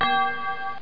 BELL_G.mp3